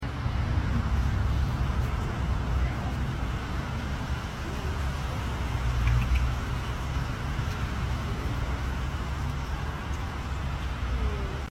This Golden Lion Tamarin is sound effects free download